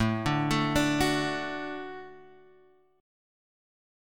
A6sus4 chord {x 0 0 2 3 2} chord